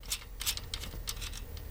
Axle Nut Twist